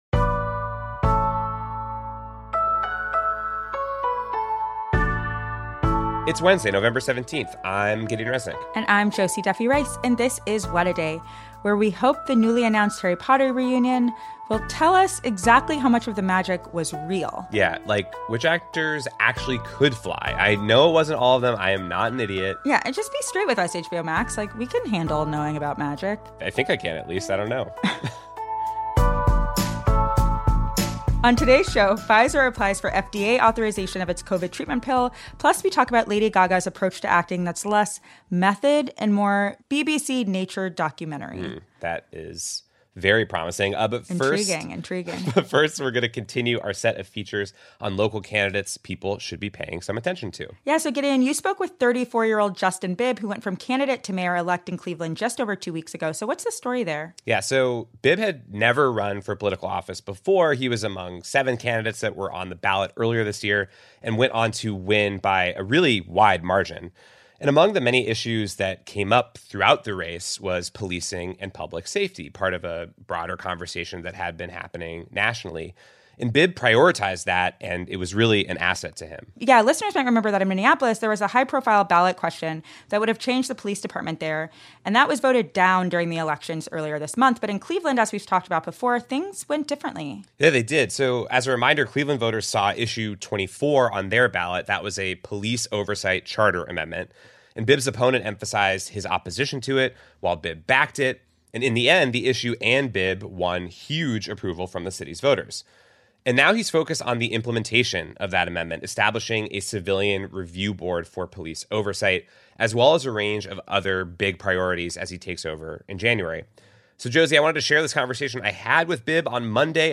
Mayor-elect of Cleveland, Justin Bibb, comes on the show to discuss his win and what he hopes to achieve in his first 100 days. The 34-year-old had never run for political office before but during the race, he prioritized public safety and policing among other issues, which proved to be successful.